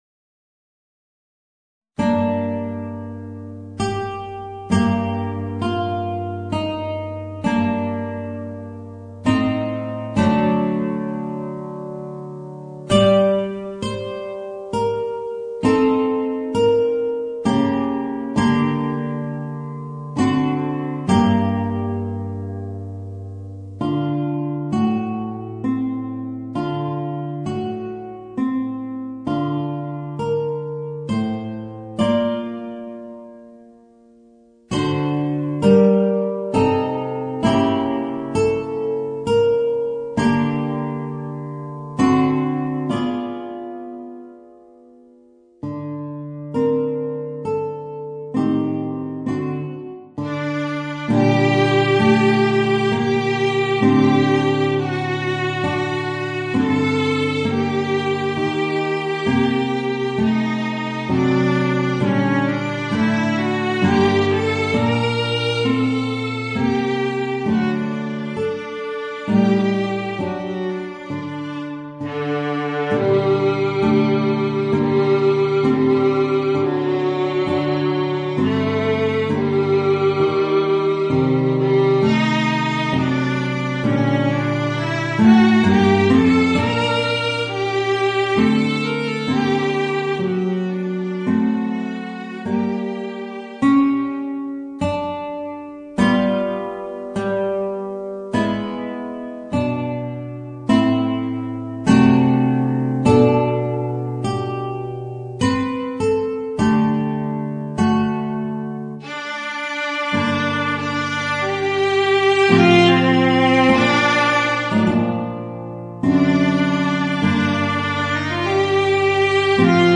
Voicing: Guitar and Viola